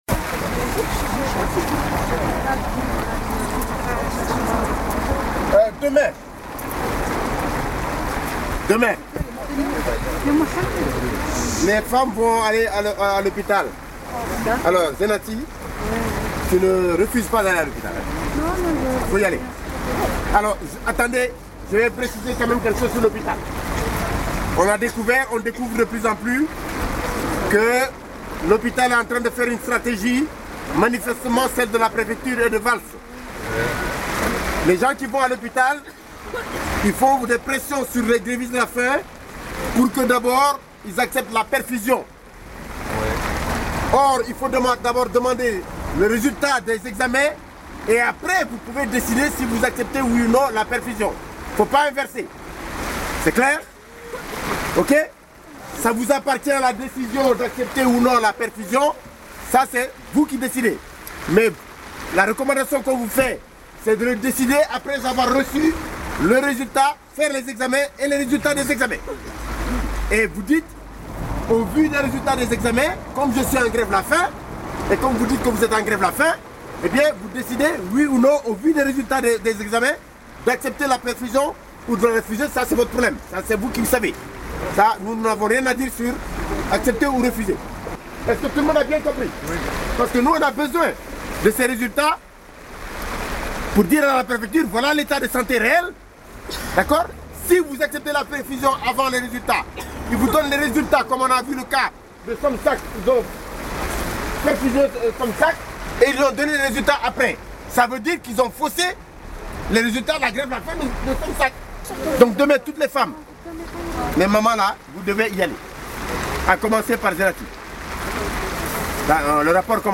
3 enregistrements audio effectués dans la tente des sans papiers du CSP59 en grève de la faim depuis 60 jours, ce Lundi 31 décembre 2012 vers 21h.
Le bruit de fond, c’est la pluie sur la toile de la tente.